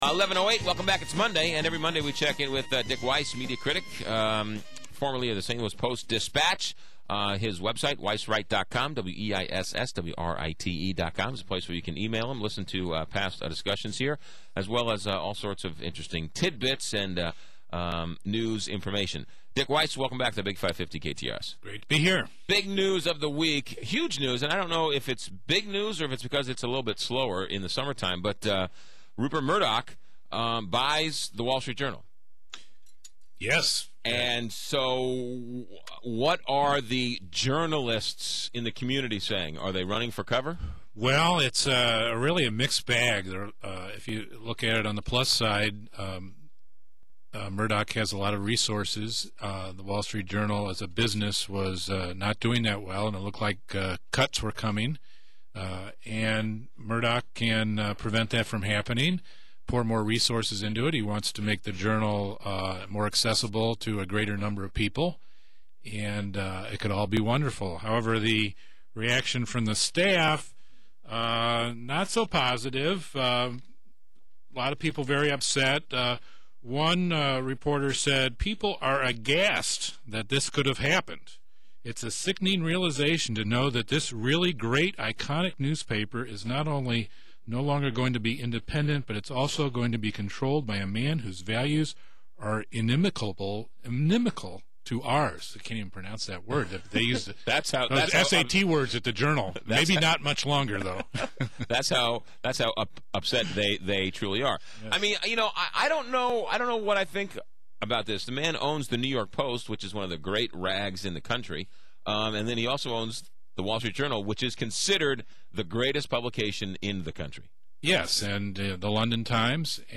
discuss the ramifications on KTRS (550 AM).